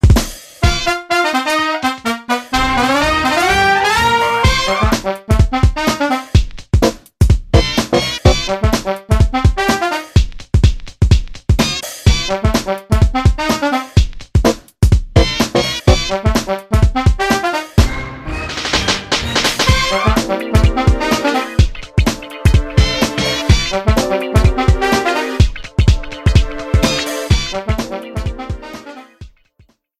Reduced length to 30 seconds, with fadeout.